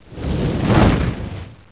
meteor.wav